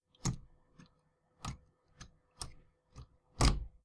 plugpull2.wav